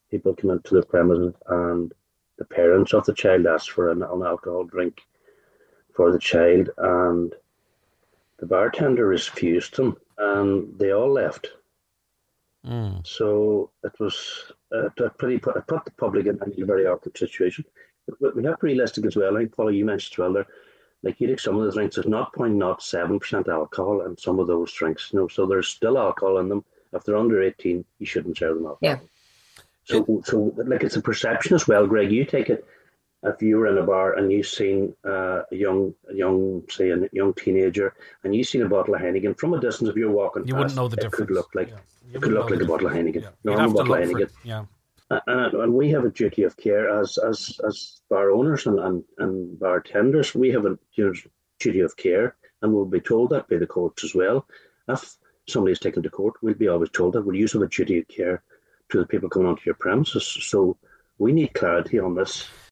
Speaking on the Nine ’til Noon Show, Cllr Harley said it cannot remain a grey area: